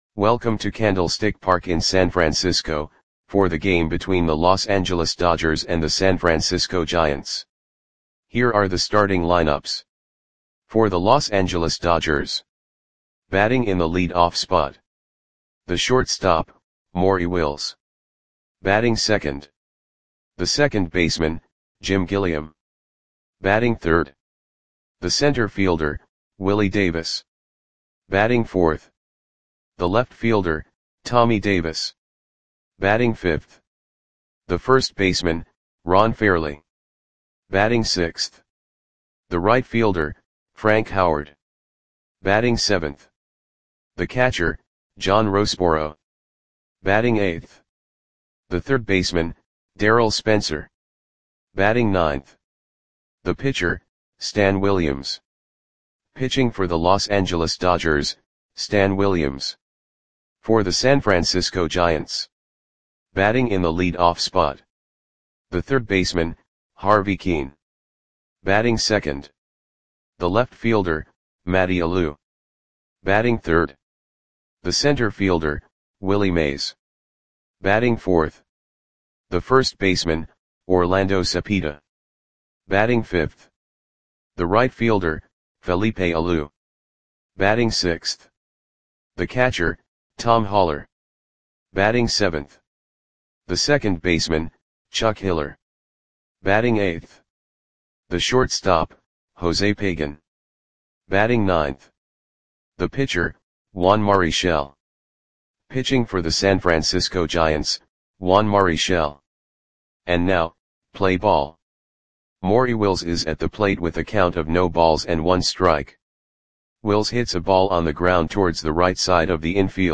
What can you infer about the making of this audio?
Lineups for the San Francisco Giants versus Los Angeles Dodgers baseball game on August 12, 1962 at Candlestick Park (San Francisco, CA).